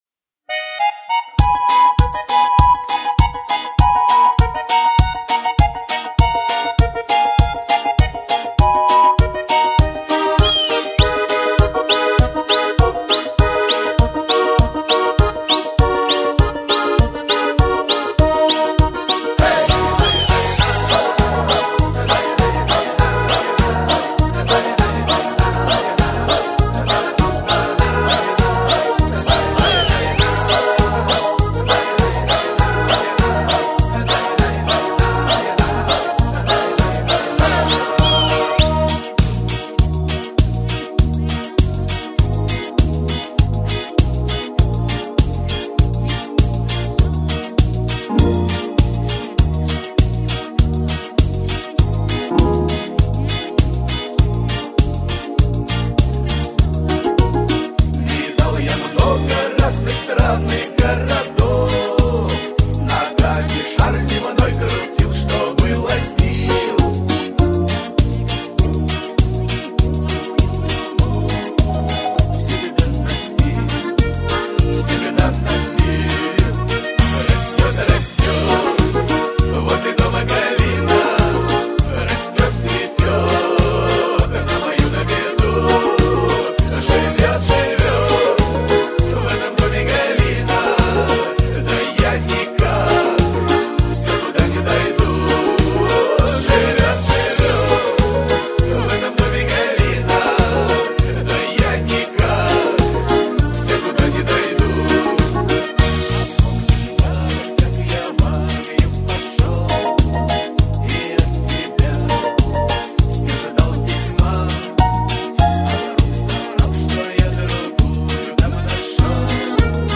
минусовка версия 40369